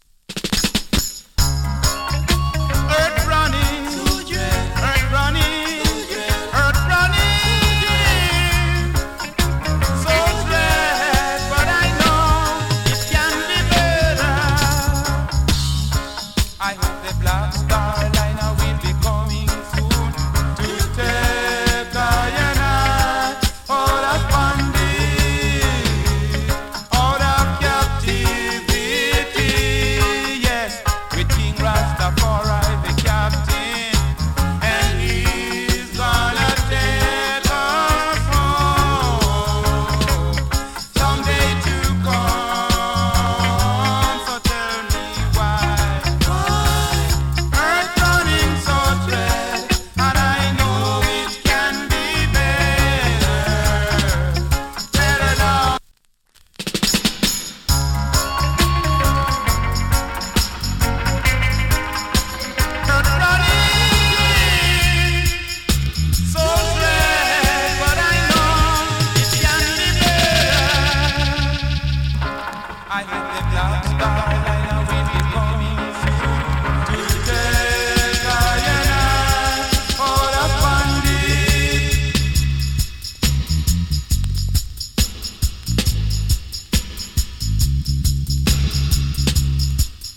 79年 FINE ROOTS VOCAL !